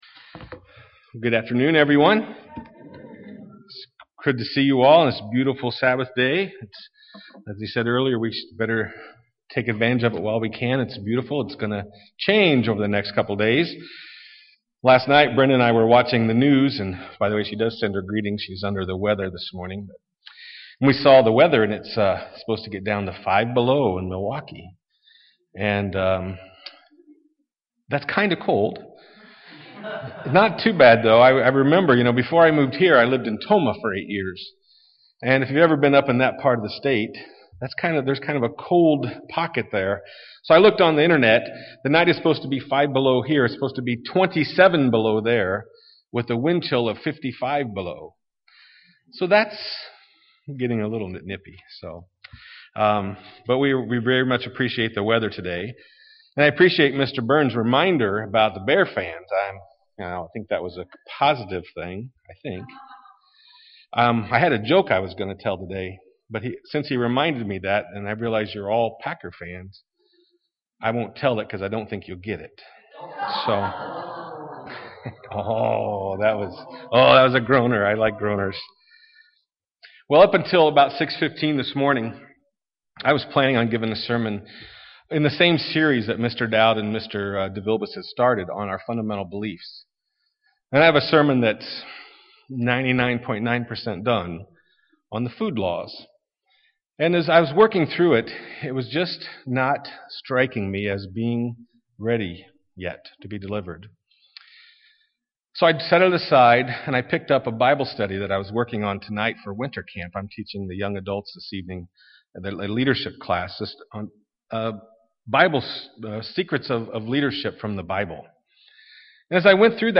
Given in Milwaukee, WI
Print Our bible has much to touch on the topic of leadership UCG Sermon Studying the bible?